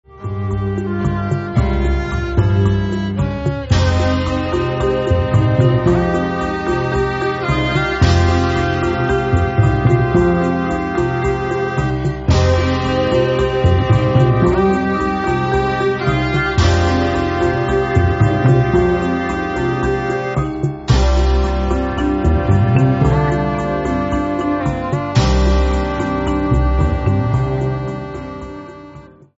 Exotic Sound